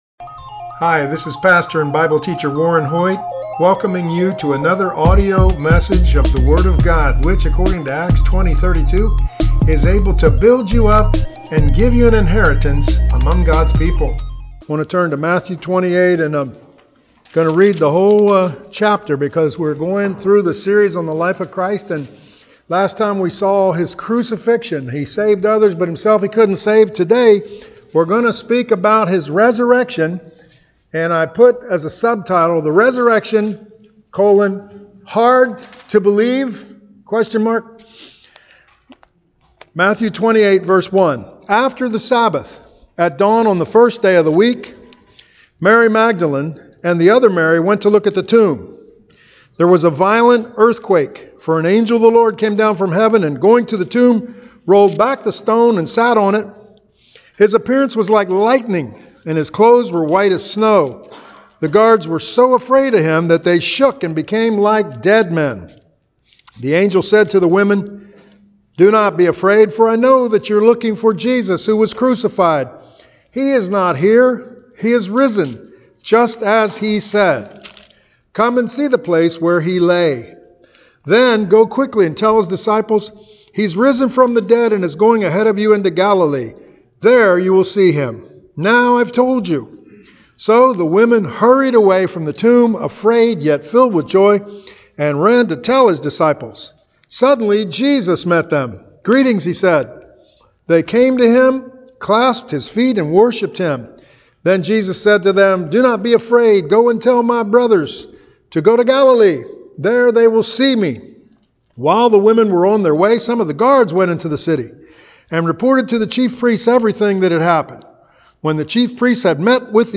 Resurrection-Sermon.mp3